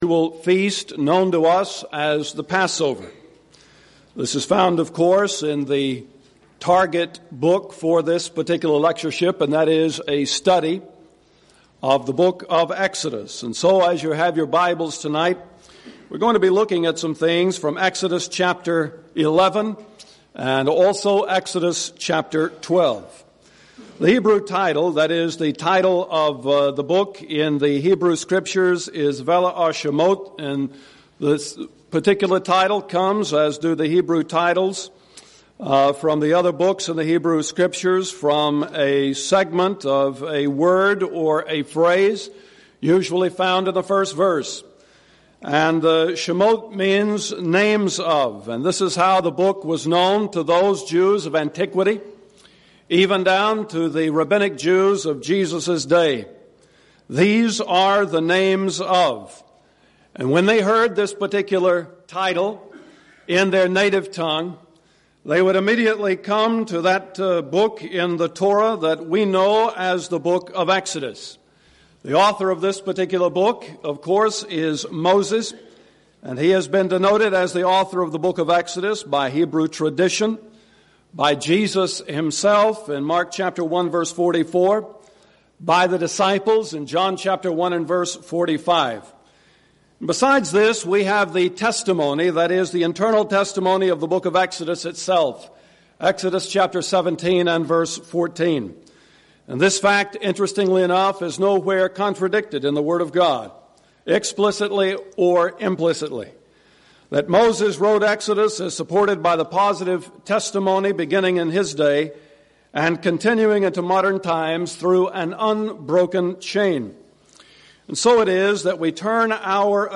Event: 2nd Annual Schertz Lectures Theme/Title: Studies In Exodus
lecture